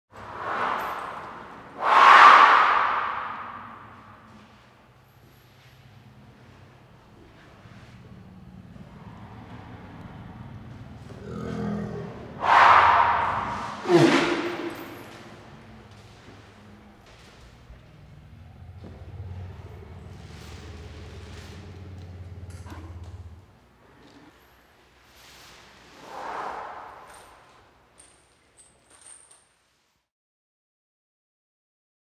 دانلود صدای فیل 1 از ساعد نیوز با لینک مستقیم و کیفیت بالا
جلوه های صوتی